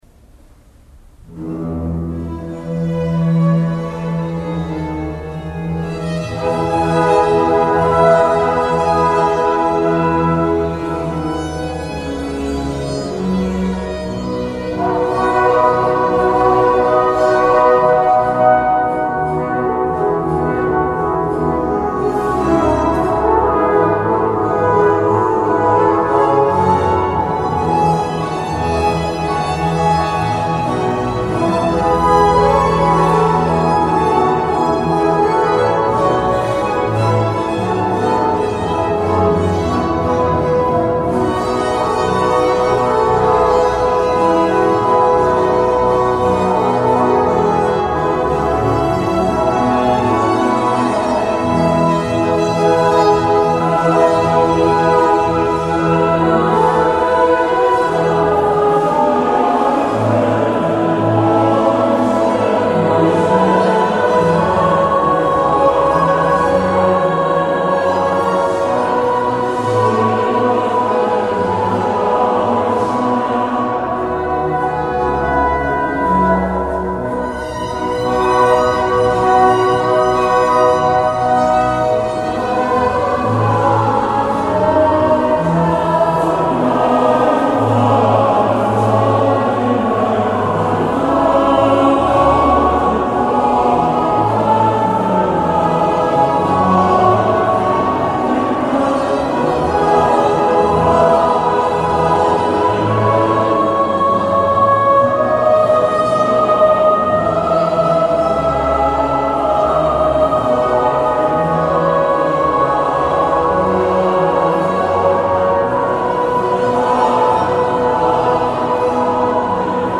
Studentenkantorei Heidelberg
Wie schön leuchtet der Morgenstern von Johann Sebastian Bach Als Choralkantate (BWV 1) im Jahre 1725 zum Fest Mariä Verkündigung (25.
Der Handy-Mitschnitt (mit viel Hall) vom Eingangschor und Schlusschoral ,
BWV_01_Eingangschor_und_Choral.mp3